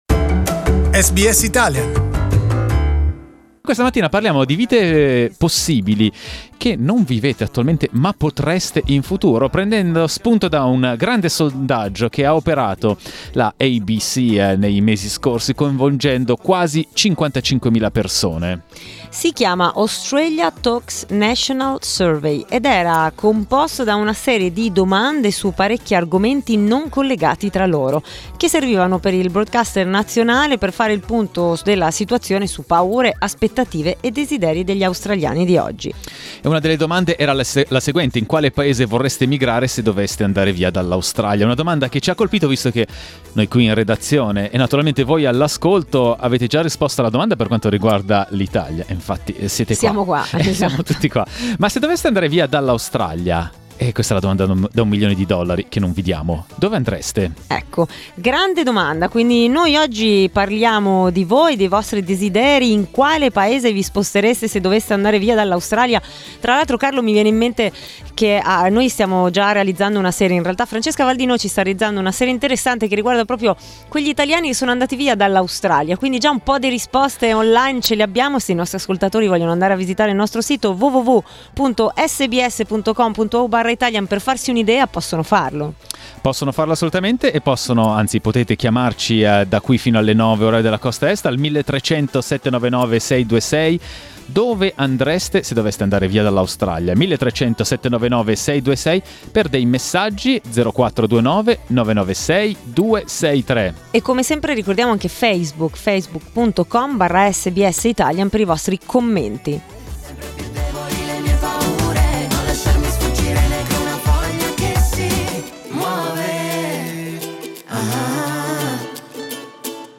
In which country would you like to live if you were to leave Australia? We talked about it this morning during our talkback. Here's what our listeners responded.